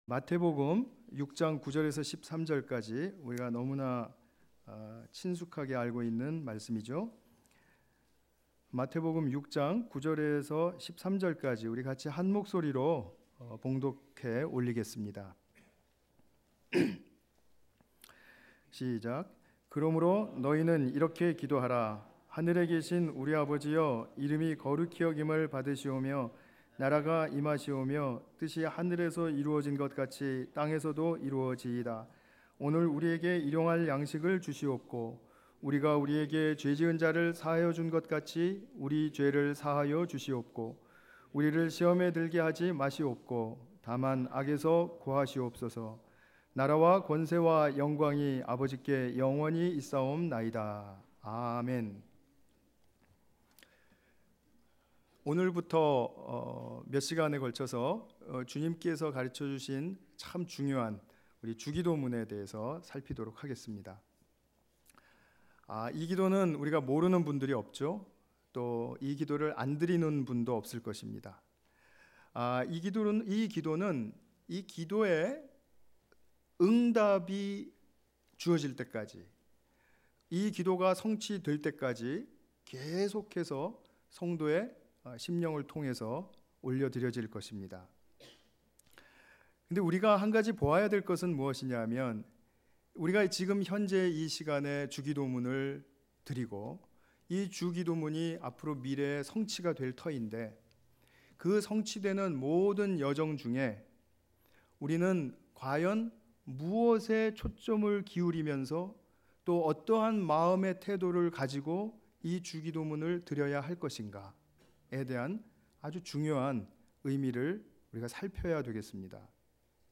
마태복음 6:9-13 관련 Tagged with 주일예배